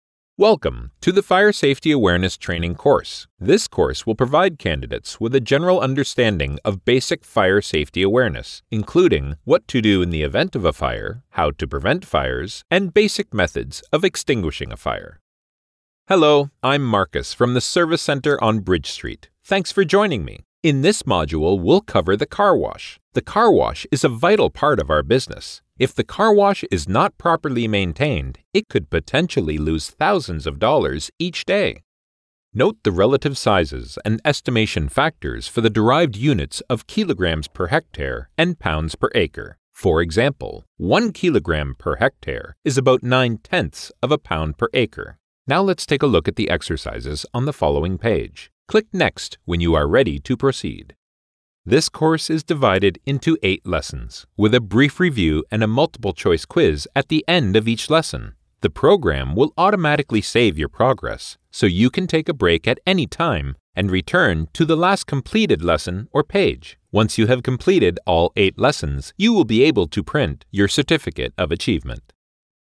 a resonant, warm baritone voice with a neutral Canadian accent
E-learning
My dedicated, broadcast-quality studio is Source Connect Certified* and features a Neumann TLM103 microphone, a Universal Audio Apollo X preamp, Audio Technical M50x Studio Headset, MacBook Pro running Adobe Audition, and a hard-wired ethernet connection with 1.5G speed.